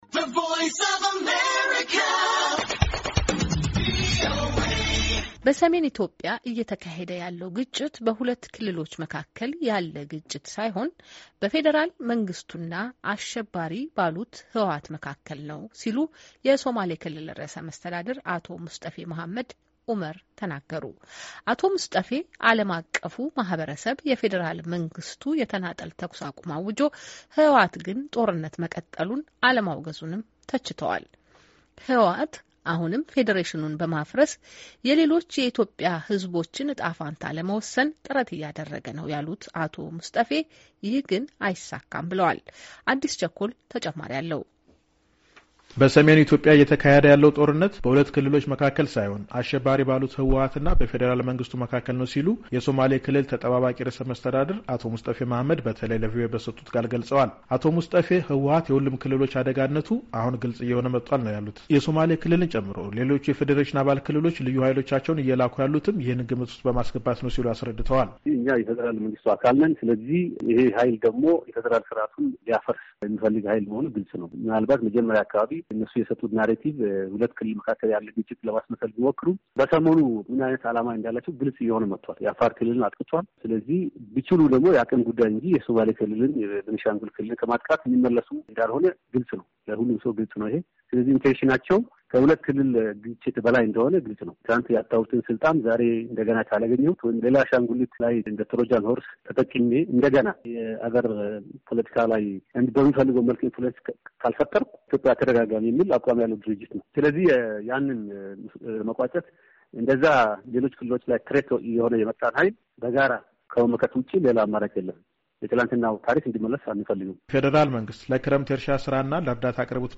ከአቶ ሙስጠፌ ሞሃመድ ጋር የተደረገ ቃለ ምልልስ